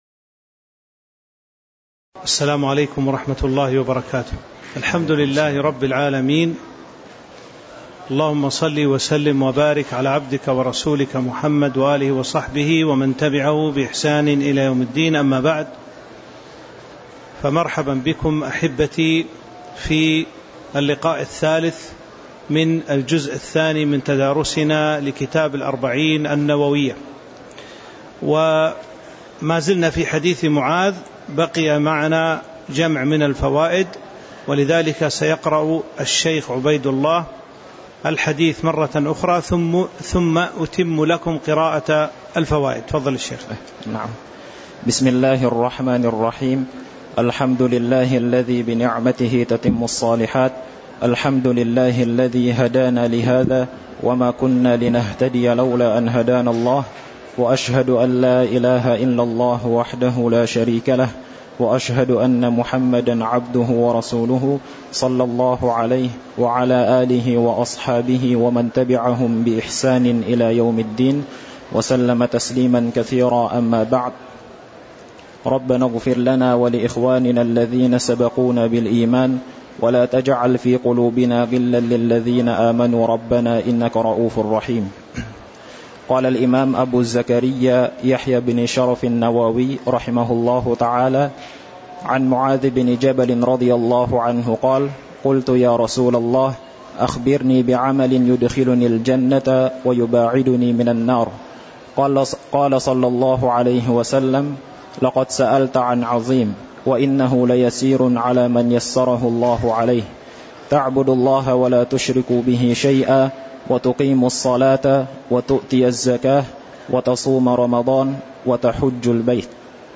تاريخ النشر ٢٠ جمادى الآخرة ١٤٤٥ هـ المكان: المسجد النبوي الشيخ